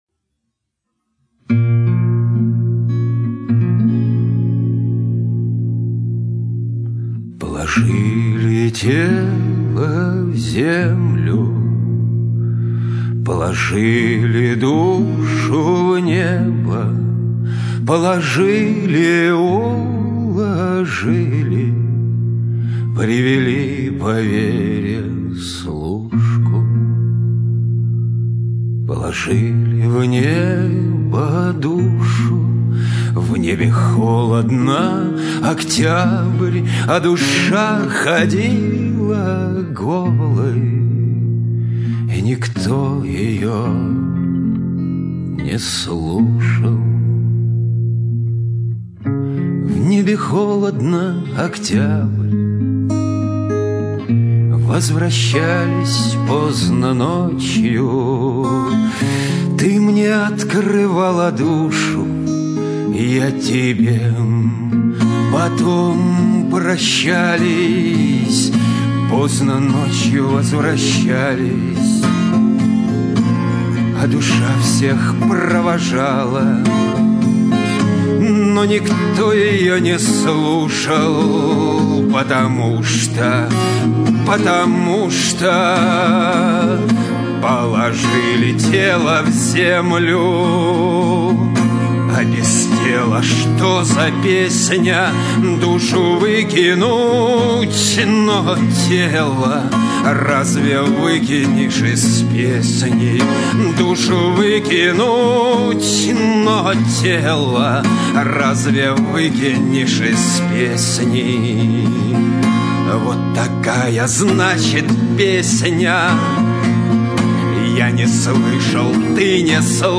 Авторская песня
Играет на 6 и 12-ти струнной гитарах.